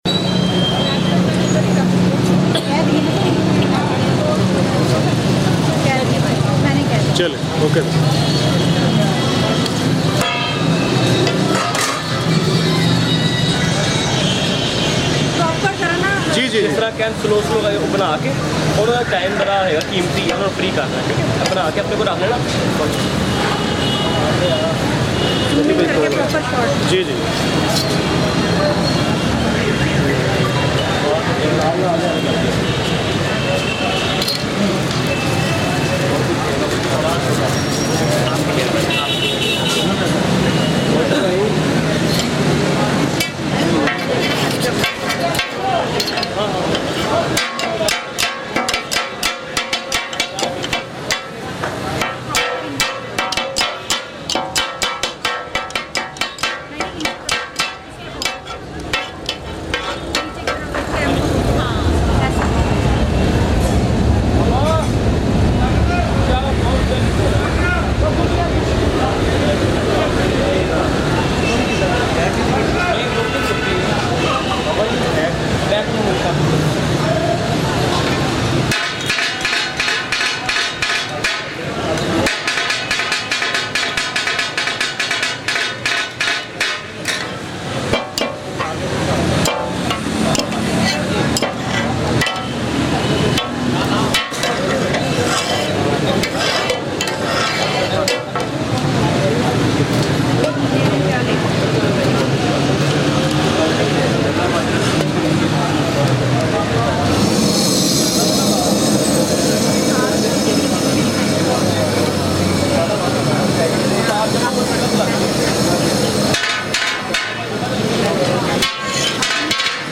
Famous Lahori Takatak Recipe#popular Food Sound Effects Free Download